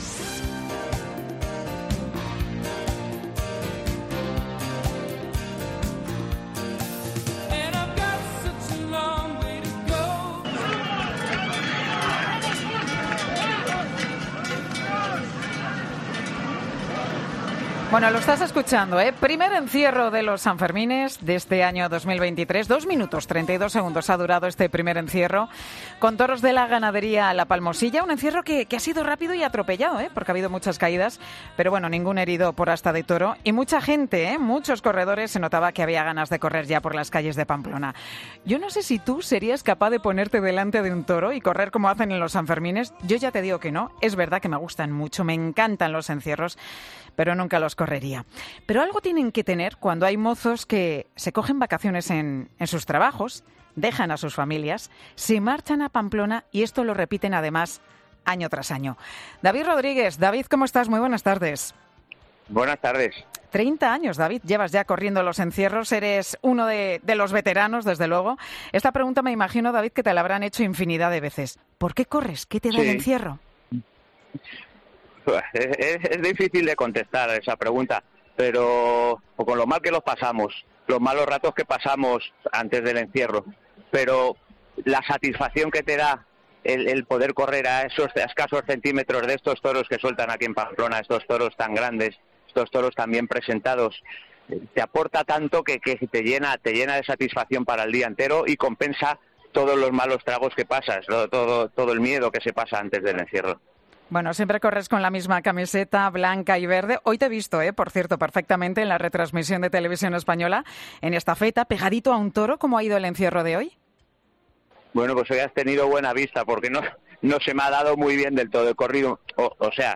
Dos corredores de los Sanfermines comparten su emoción en 'Mediodía COPE'